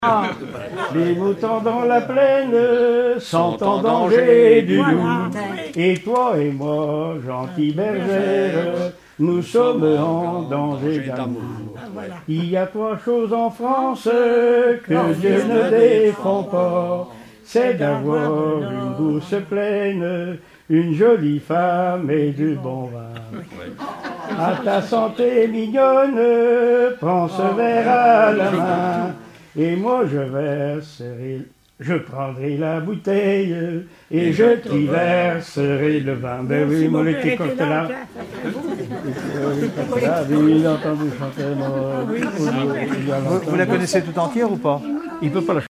Genre strophique
Regroupement de chanteurs du canton
Pièce musicale inédite